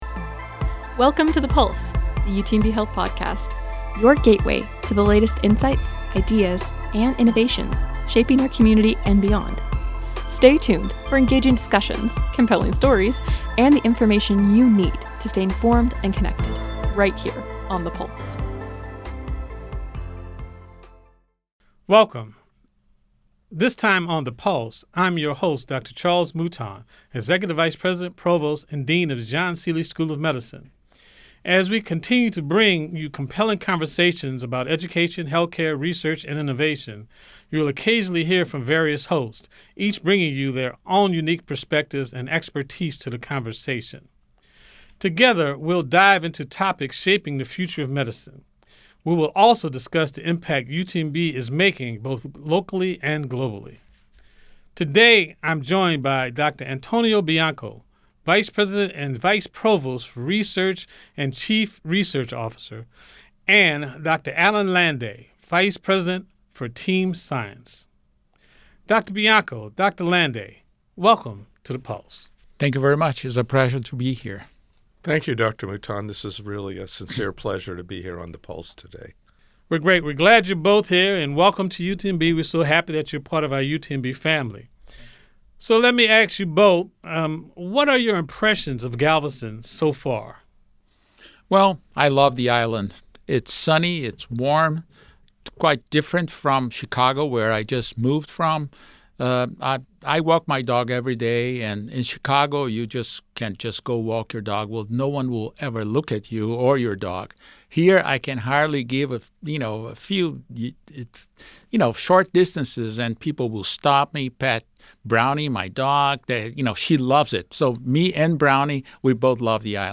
a lively conversation